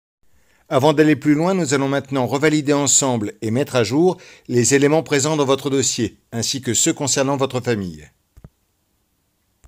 - Baryton